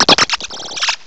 sovereignx/sound/direct_sound_samples/cries/noibat.aif at master
noibat.aif